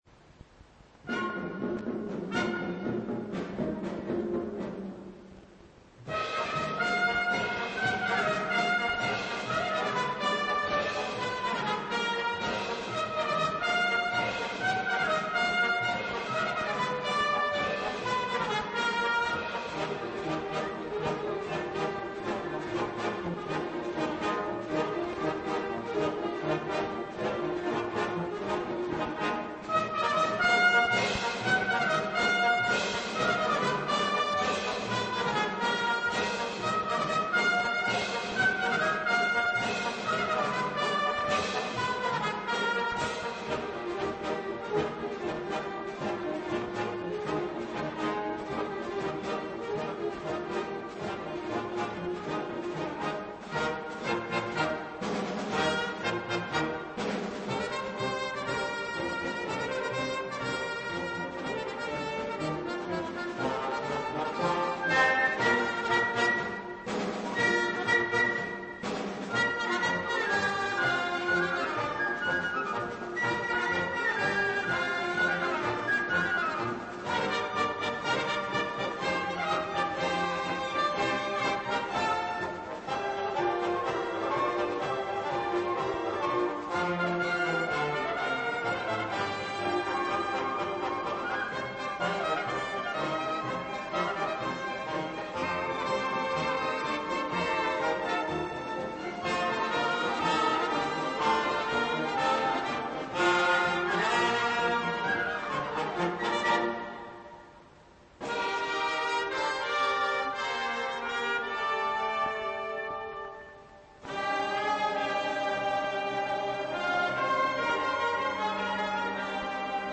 musiqili komediyadan fraqmentlər